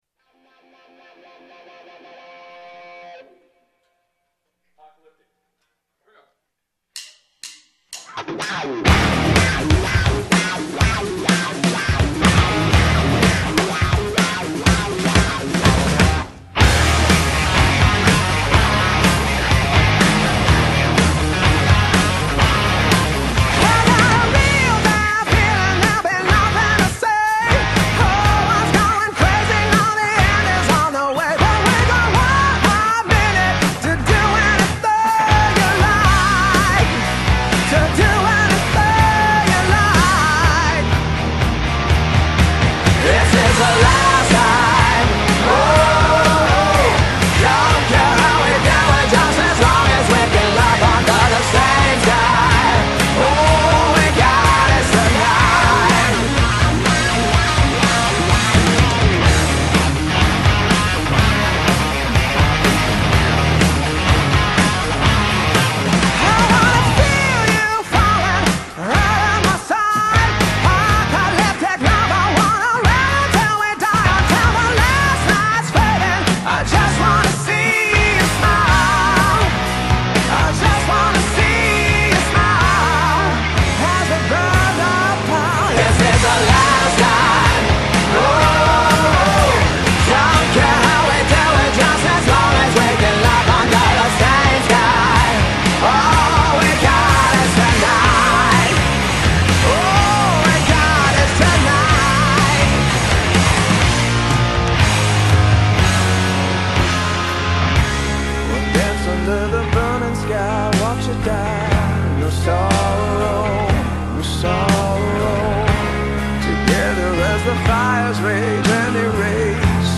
Recorded at R.S. Studios, Canoga Park, LA 1990 ...